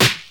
• 2000s Hip-Hop Snare Drum B Key 04.wav
Royality free snare tuned to the B note. Loudest frequency: 3113Hz